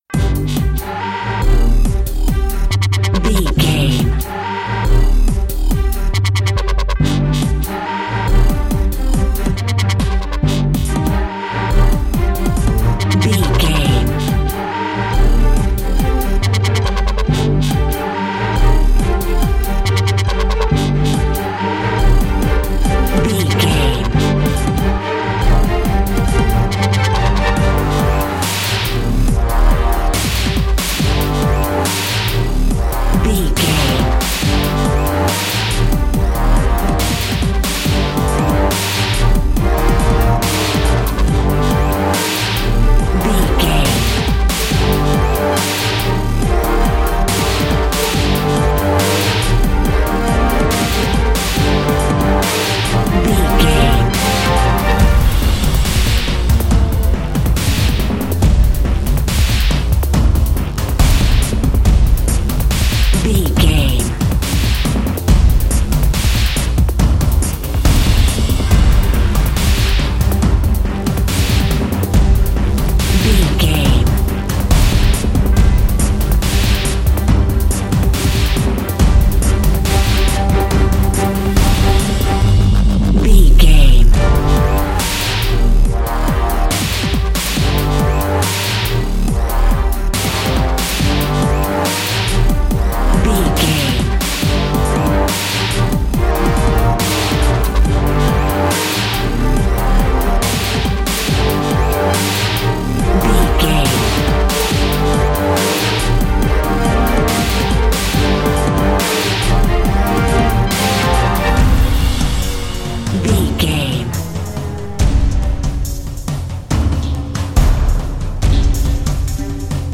Aeolian/Minor
Fast
strings
drum machine
horns
orchestral
orchestral hybrid
dubstep
aggressive
energetic
intense
synth effects
wobbles
driving drum beat
epic